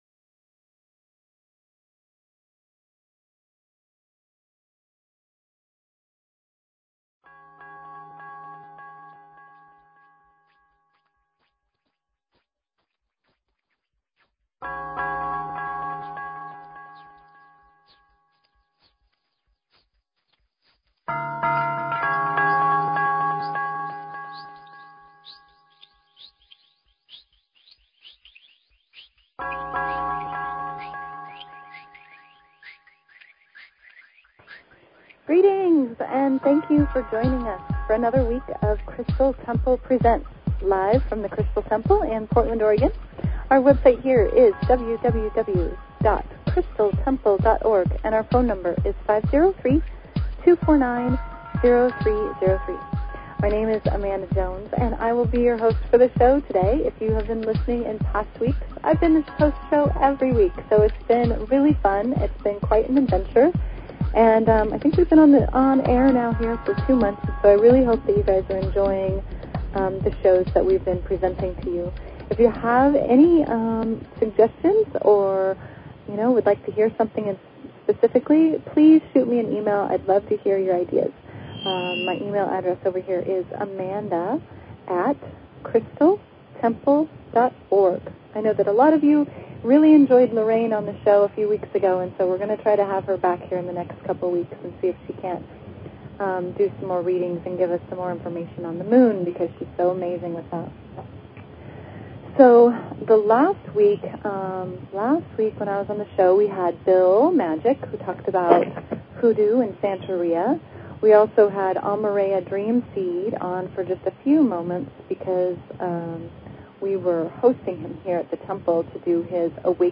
Talk Show Episode, Audio Podcast, Crystal_Temple_Presents and Courtesy of BBS Radio on , show guests , about , categorized as